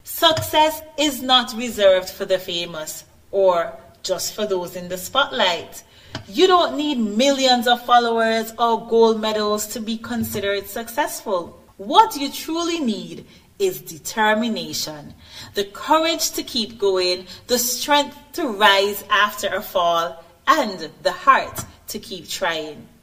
Surrounded by the support and pride of family members, teachers, staff and supporters, the Joycelyn Liburd Primary School held its Graduation and Prize Giving Ceremony on July 1st, 2025.